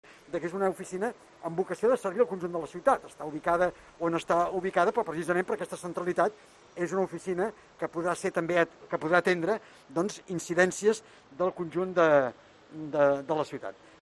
El teniente de alcalde, Albert Batlle ha declarado